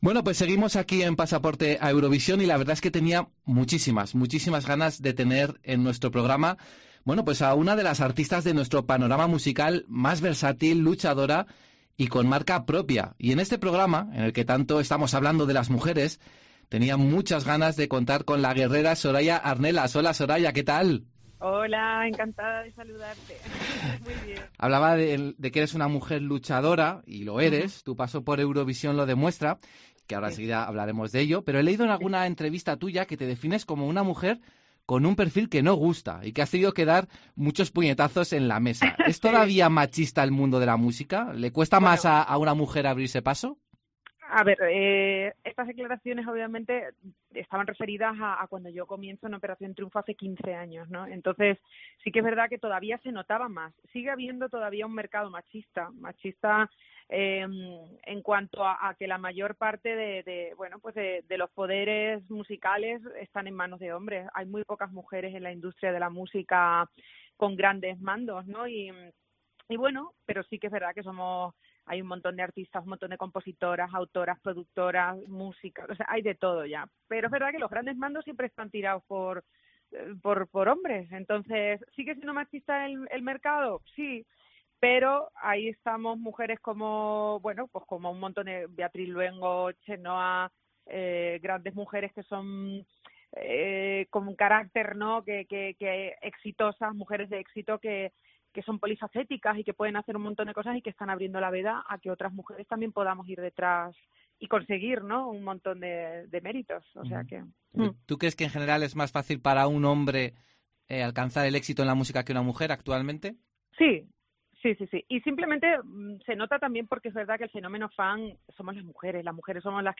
En una entrevista en el programa "Pasaporte a Eurovisión", Soraya confiesa que no es capaz de poder ver su actuación en aquella noche en Moscú.